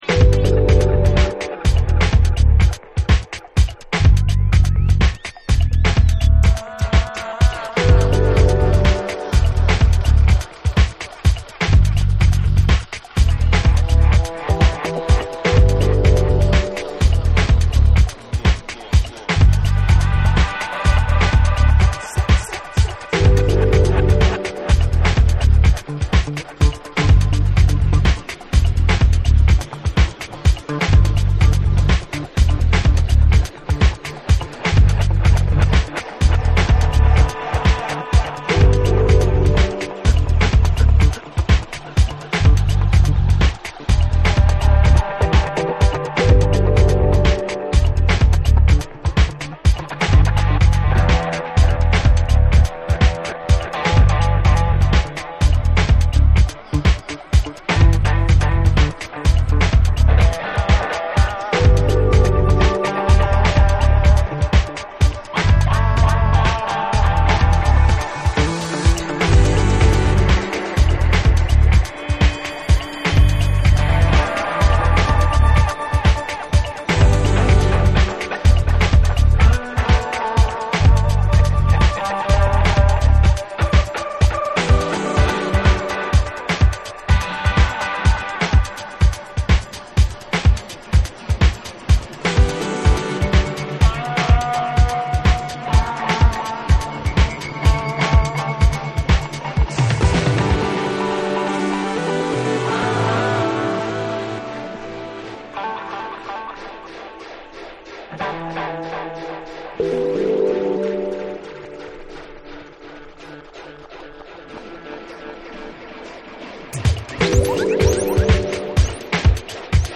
※目立つキズが数本入ってますが、音には然程影響ございません（SAMPLEをご参照ください）。
TECHNO & HOUSE / DISCO DUB